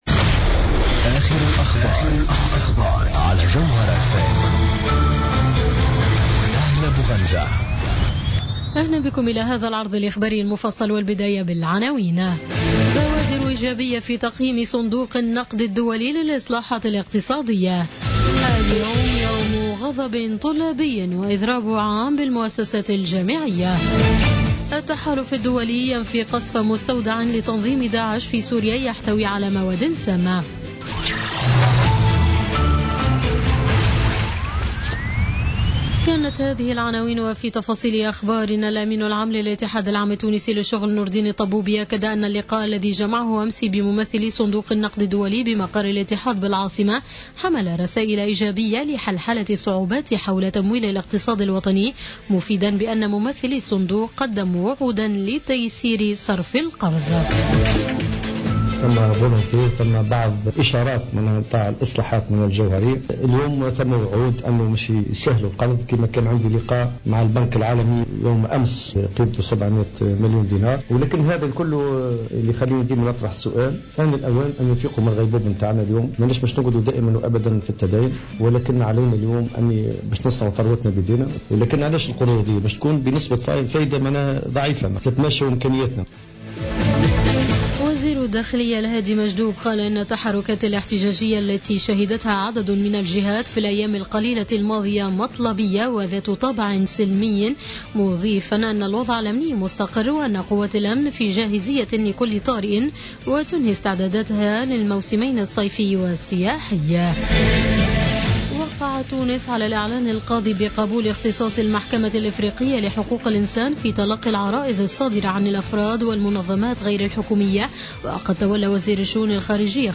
نشرة أخبار منتصف الليل ليوم الجمعة 14 أفريل 2017